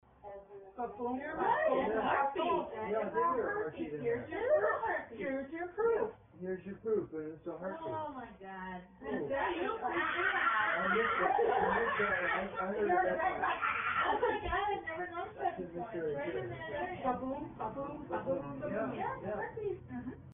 EVPs (Electronic Voice Phenomena)
Odd squeal It sounds like a monkey squealing but there were no monkeys or any other exotic creatures present.
odd_squeal.mp3